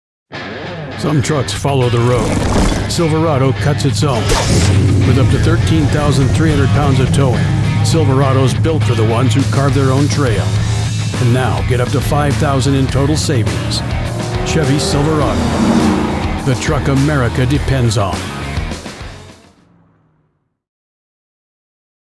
Gritty, Rugged, American Voiceover
• Gritty, masculine tone with Western authenticity
• Cinematic pacing perfect for national TV and radio
Truck Commercial Voiceover Demos
• Fully treated booth